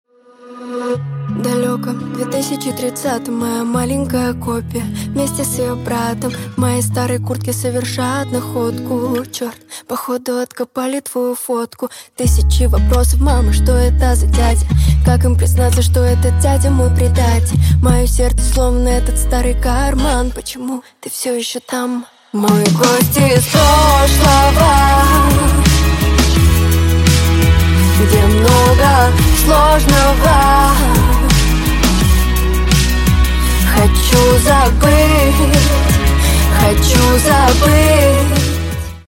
Грустные Рингтоны
Поп Рингтоны